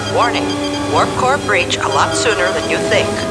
tinker_lcars.wav